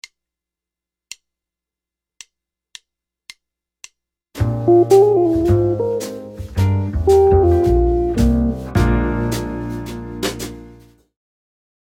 Hundreds of famous guitar licks all in one easy to use reference library.